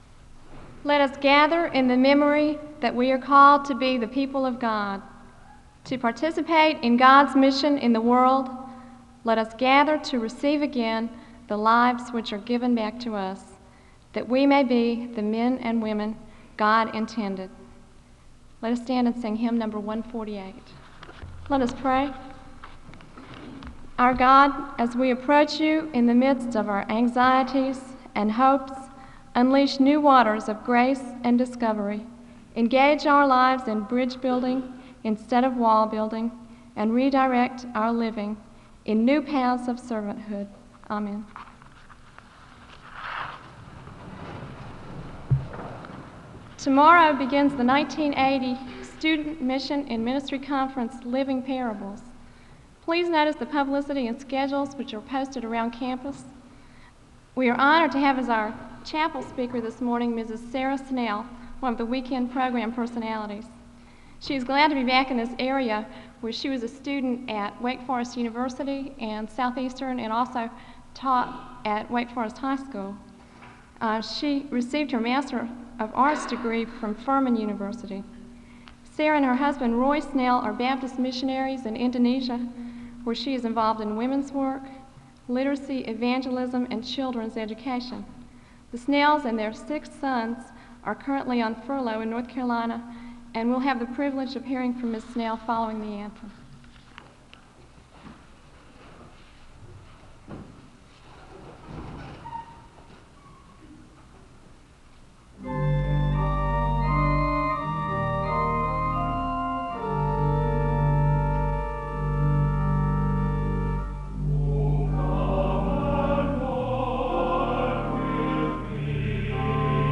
The service begins with a word of prayer (00:00-00:41).
The choir sings a song of worship (01:40-06:07).
The choir ends the service with a song of worship (31:24-32:12).
Missions--Sermons
Location Wake Forest (N.C.)